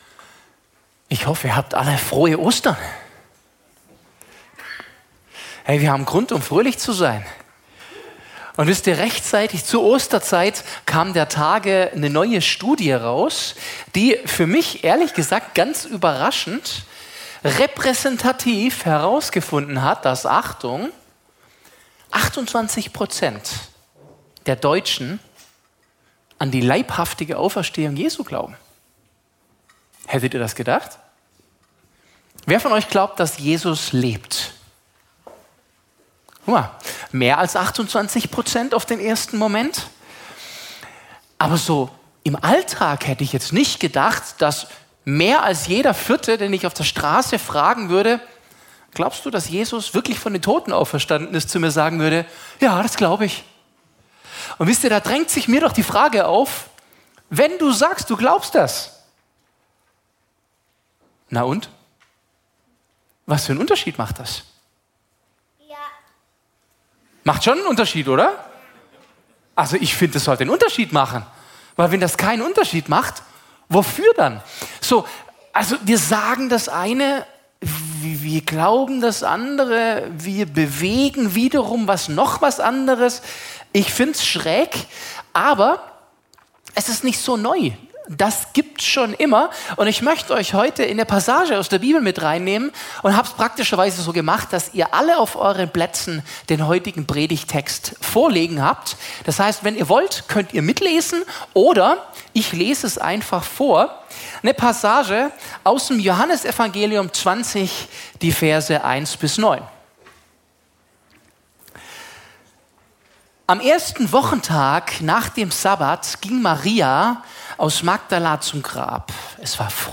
Gottesdienst – Sonntagmorgen – HOPE Kirche Podcast